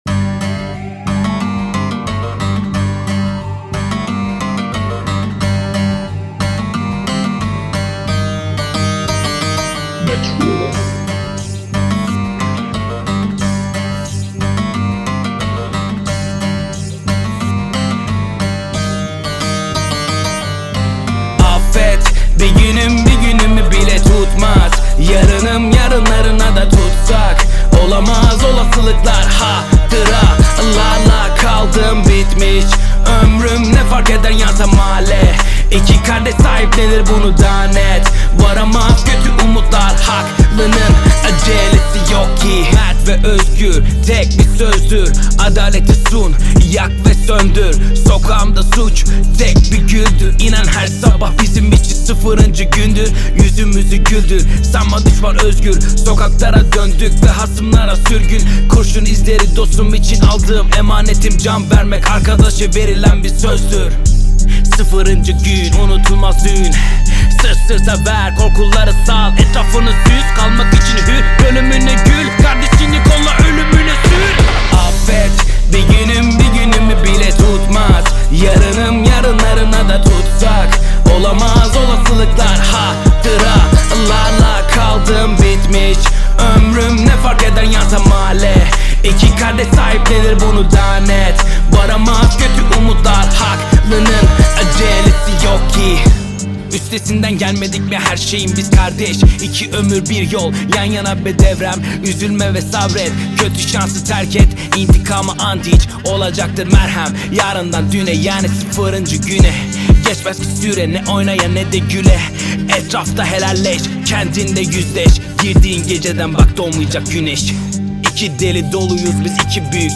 heyecan aksiyon enerjik şarkı.